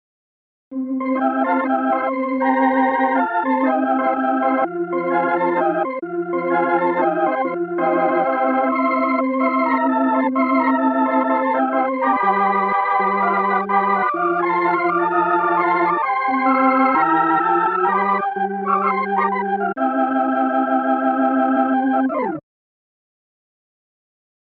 04-Optigan Organ 1 L
04-Optigan-Organ-1-L.mp3